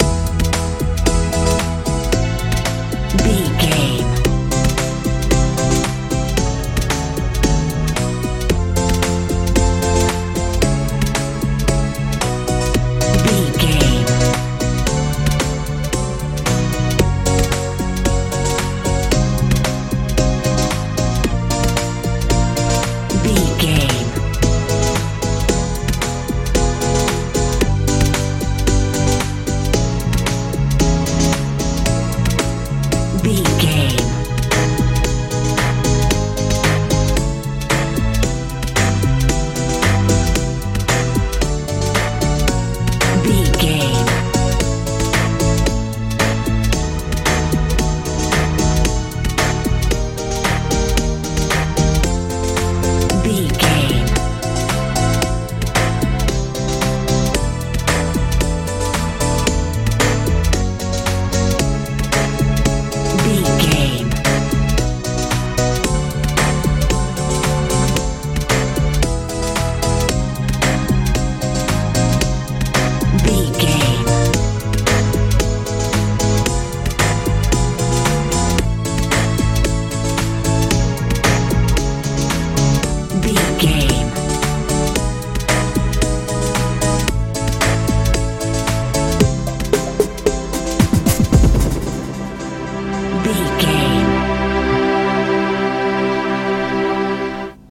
modern pop feel
Ionian/Major
hopeful
joyful
synthesiser
bass guitar
drums
80s
90s
strange
bright